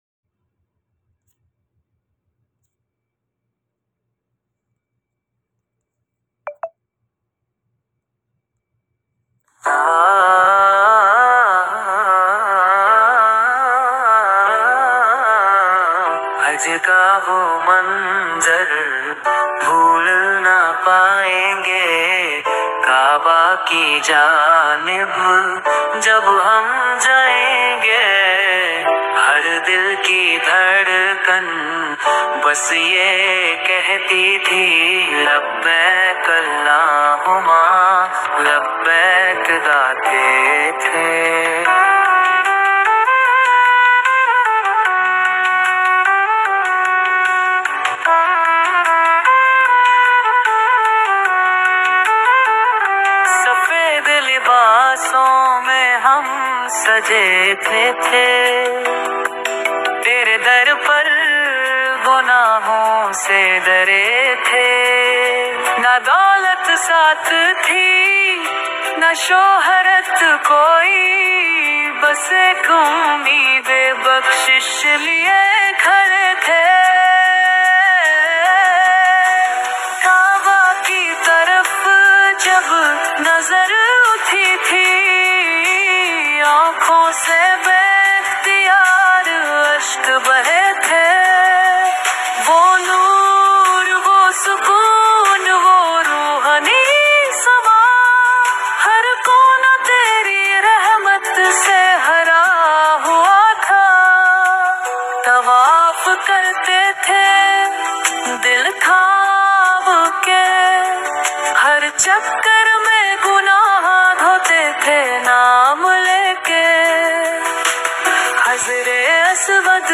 Removing Background Music naat
As it is a mono (not stereo) track, need AI to isolate/remove singing.